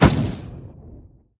Minecraft Version Minecraft Version snapshot Latest Release | Latest Snapshot snapshot / assets / minecraft / sounds / entity / shulker / shoot2.ogg Compare With Compare With Latest Release | Latest Snapshot
shoot2.ogg